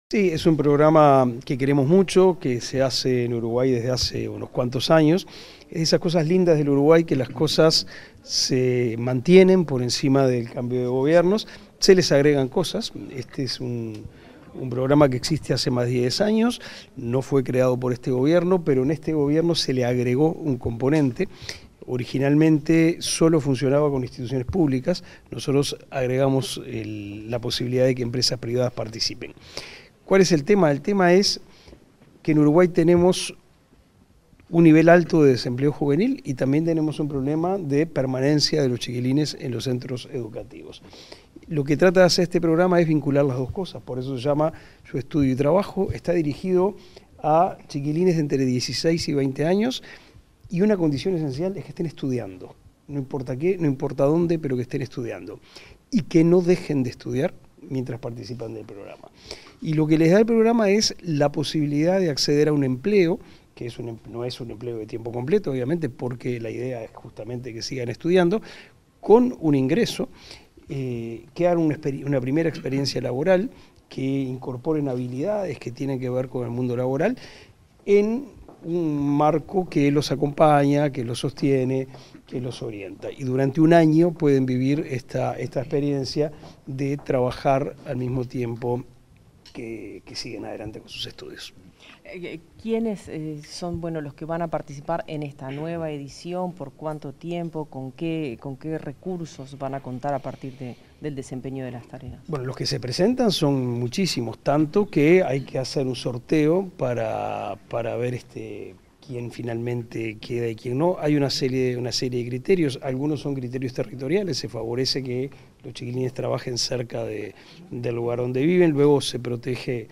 Declaraciones del ministro de Educación y Cultura, Pablo da Silveira